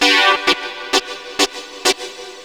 SYNTHLOOP2-R.wav